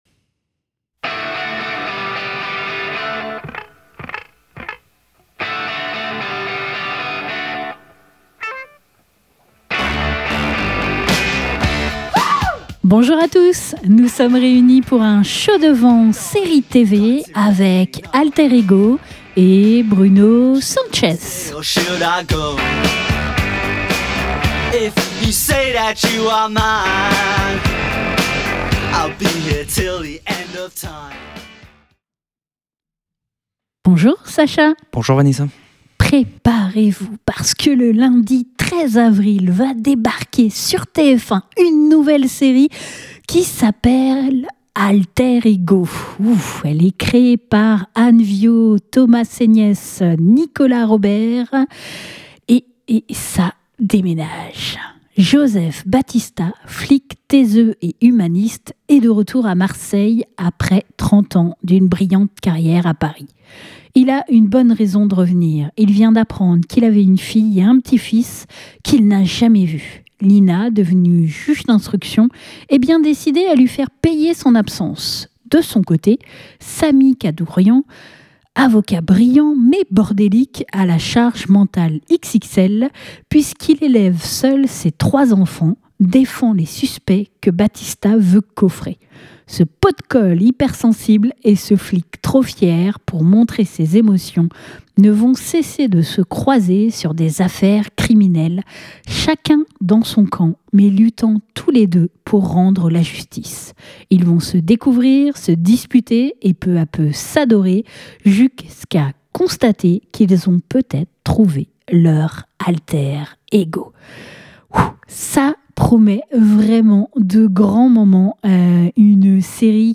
Bruno Sanches nous a accordé une interview téléphonique pour revenir sur son rôle et l'équipe qui l'entoure.